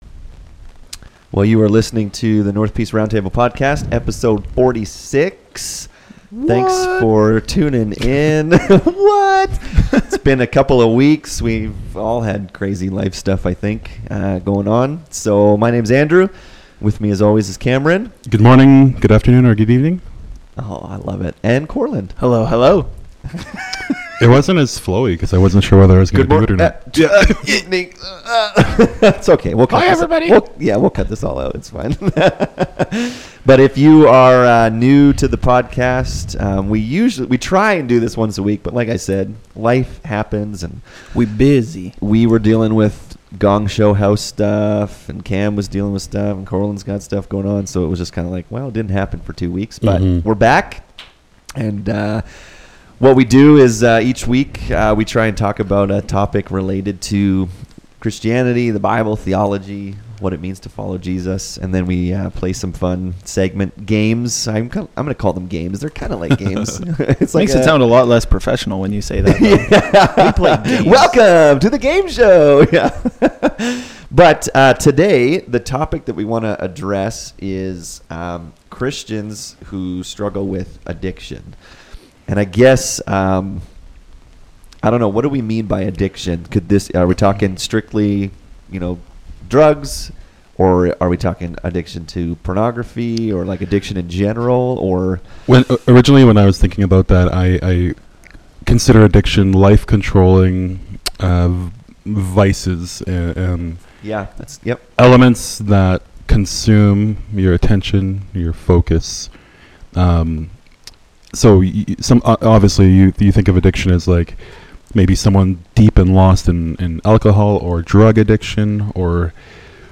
In this episode the guys talk about Christians who suffer with addictions. What does the gospel look like in that situation?